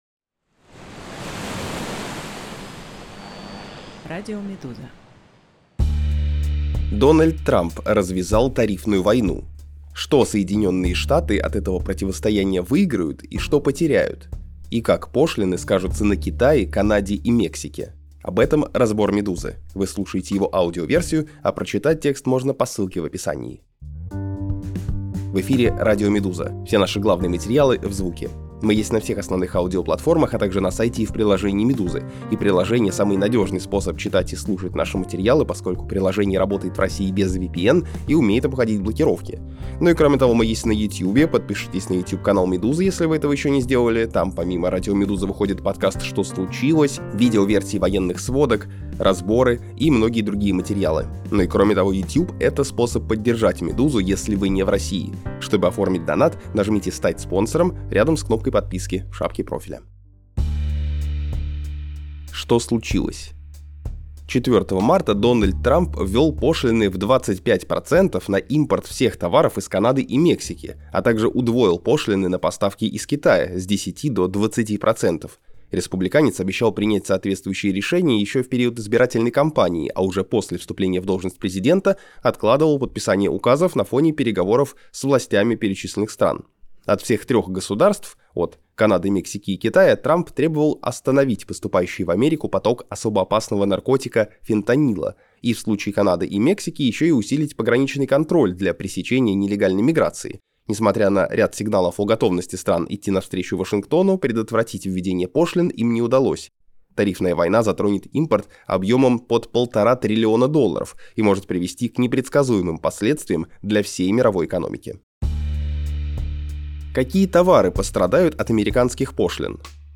Аудиоверсии главных текстов «Медузы».